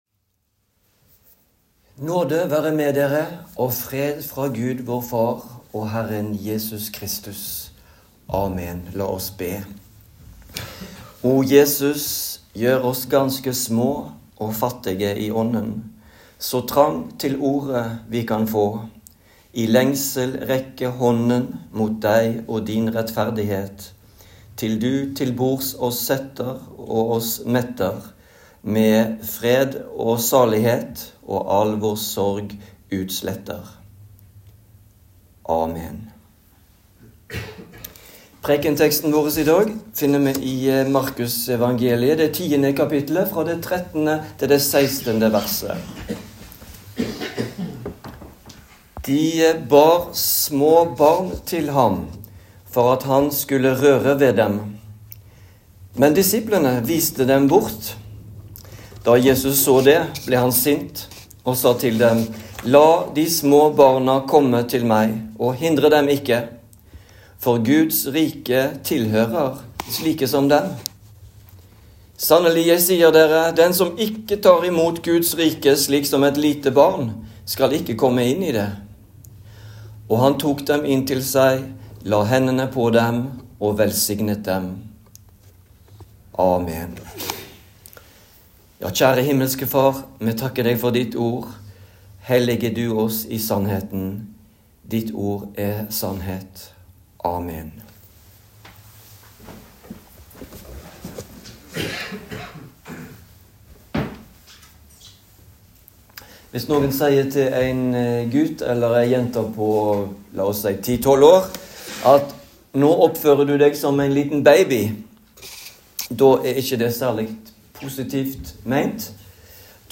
Preken
i Den Lutherske Forsamling i Avaldsnes